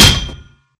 Sound / Minecraft / mob / zombie / metal2.ogg
metal2.ogg